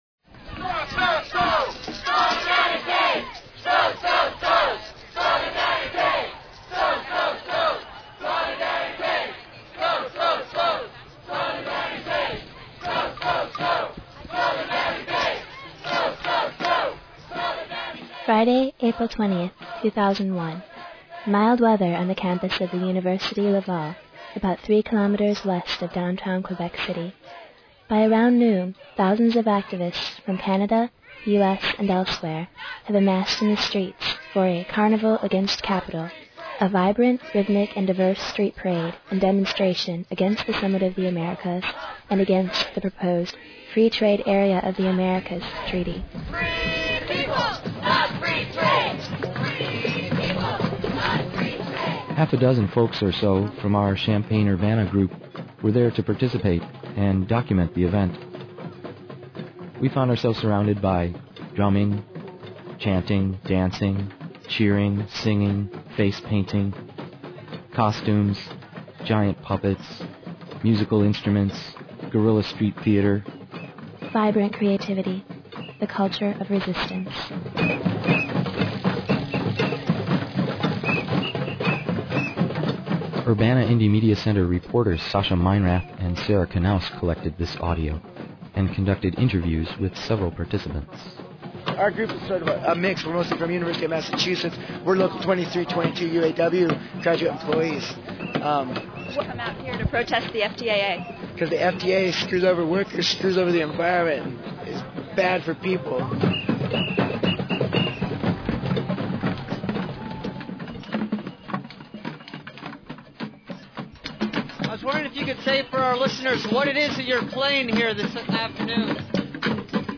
Miscellaneous u-c_imc_ftaa_01carnival.mp3 (1811 k) Just days after arriving home from Quebec City and the protests surrounding the Summit of the Americas, Urbana-Champaign Independent Media Center journalists compiled a half-hour document of their journey and experiences on the streets of Quebec City. Part One--An audio portrait of the Carnival Against Capital which took place in Quebec City on April 20, 2001, along with an interview with a representative of the Canadian Auto Workers who was participating in a direct action march to the security perimeter that same day